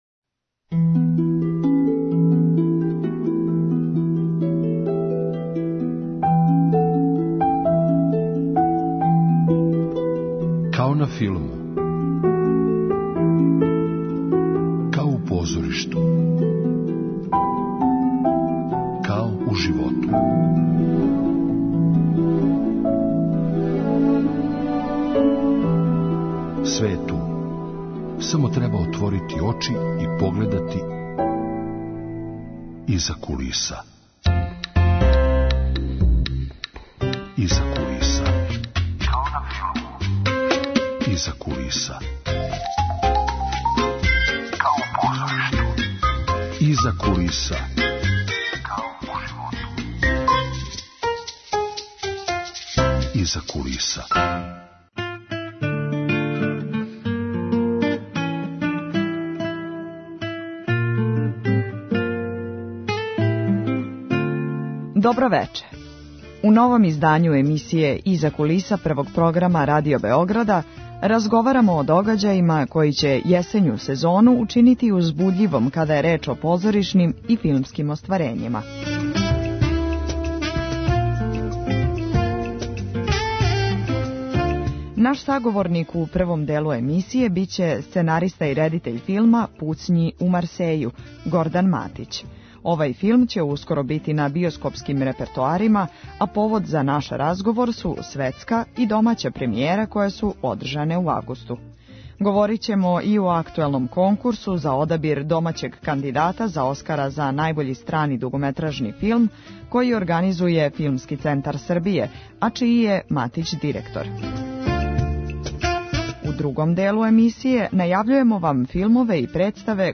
Емисија о филму и позоришту.